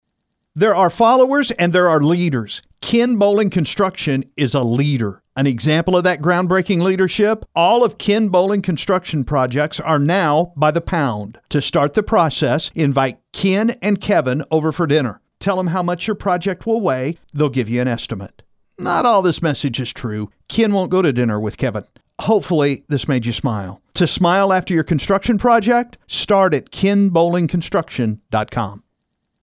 By The Pound Radio Ad